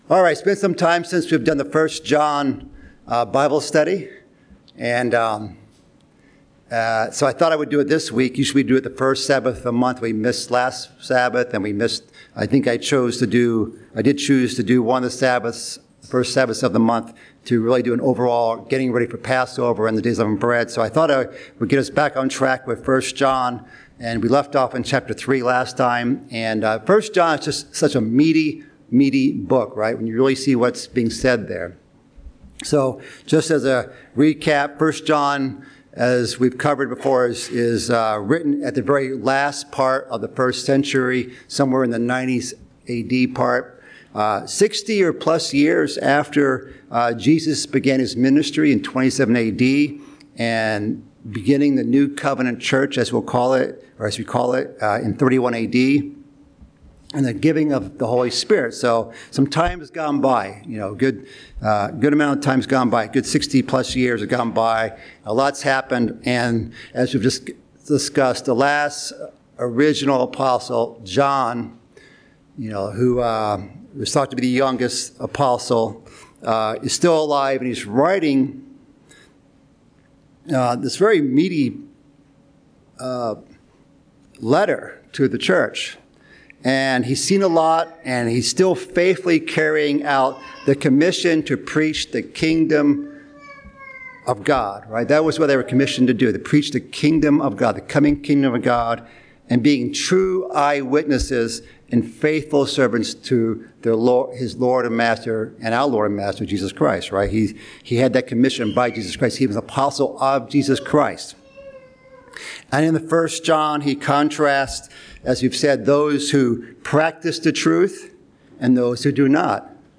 Bible Study: 1 John